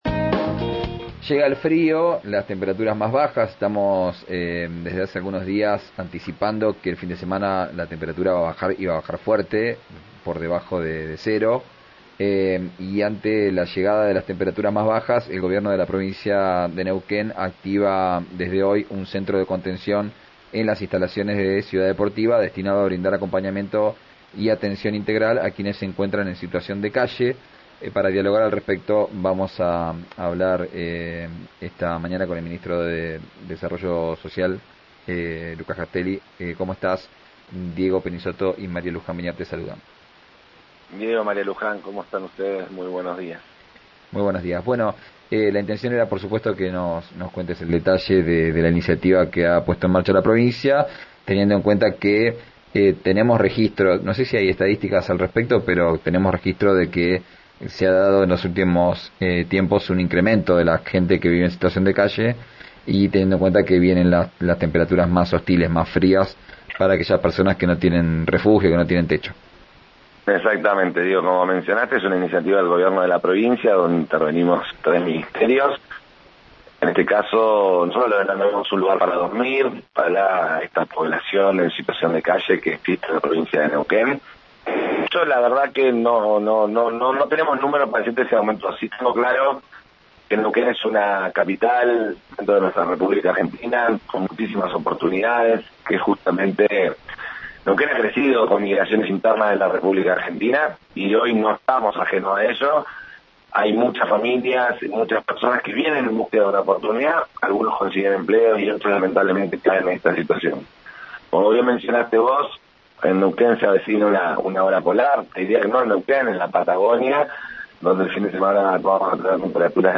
Escuchá a Lucas Castelli, ministro de Trabajo en RÍO NEGRO RADIO: